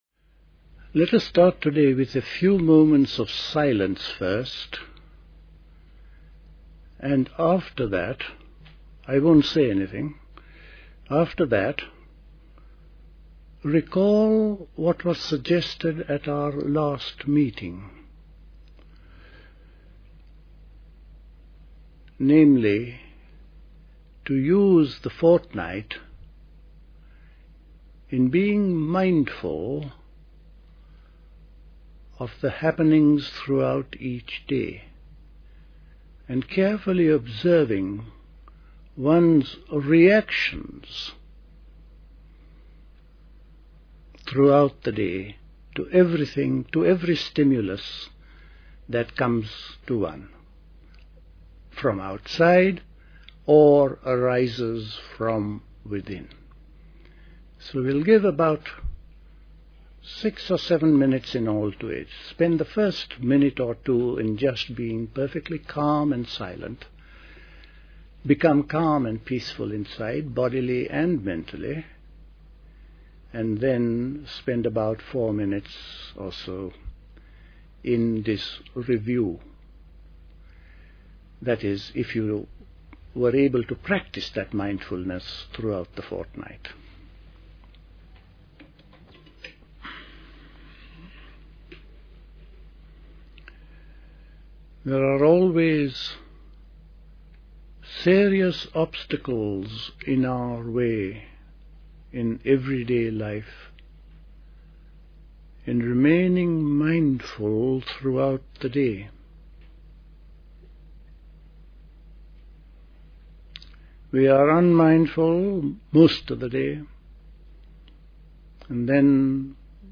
Order Talk on CD